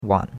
wan3.mp3